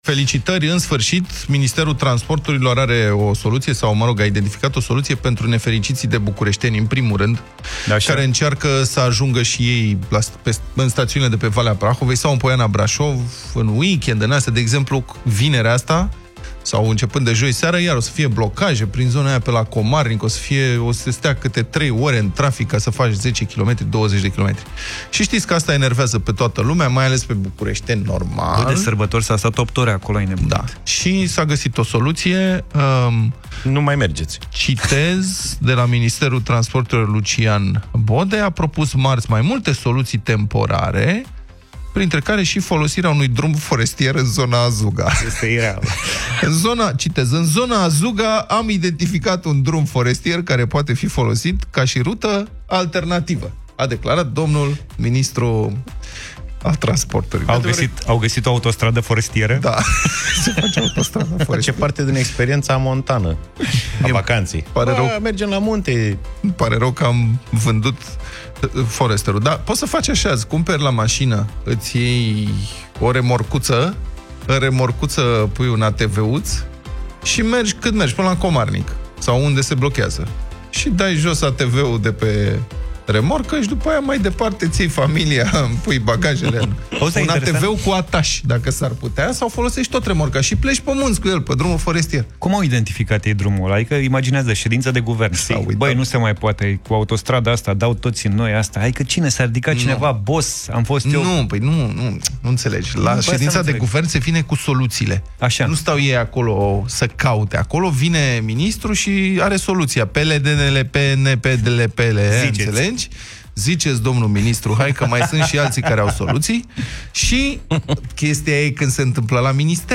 au vorbit despre acest subiect în Deșteptarea